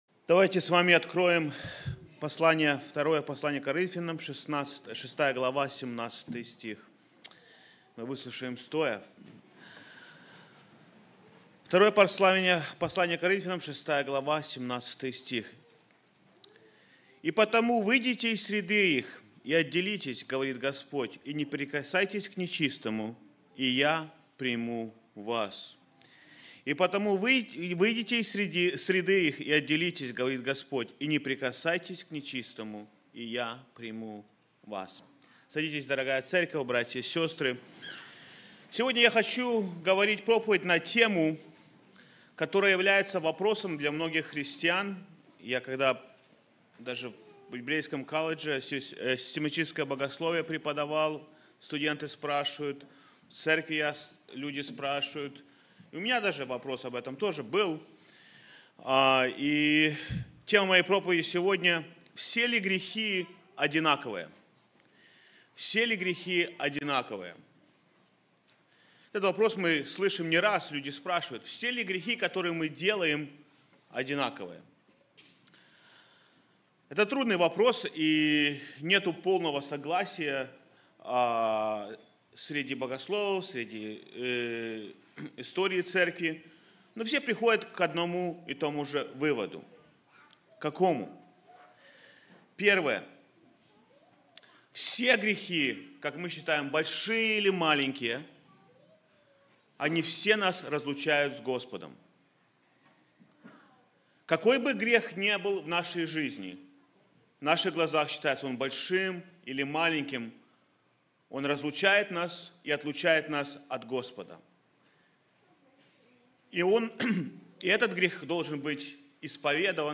В этой проповеди мы рассмотрим различные аспекты греха, их последствия и то, как они влияют на наши отношения с Богом.
sermon-not-all-sins-the-same.mp3